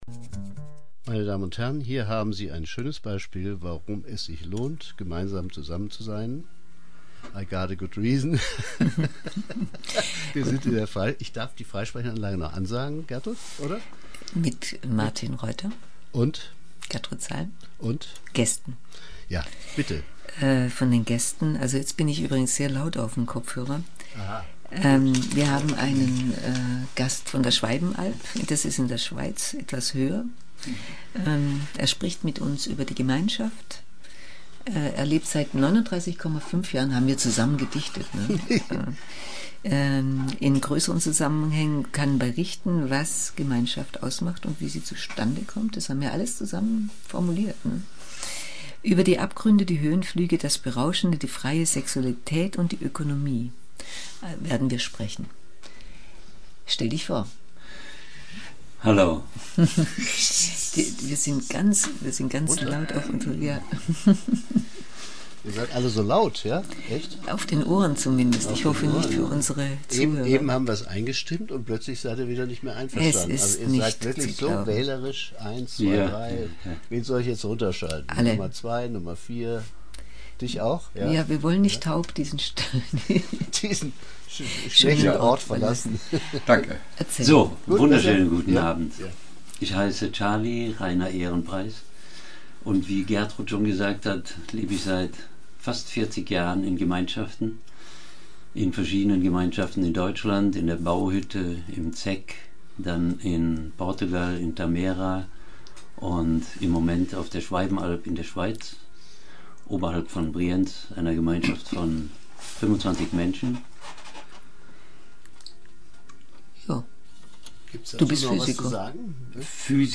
Und dann die Sendung, diesmal mit Gema-freier Musik.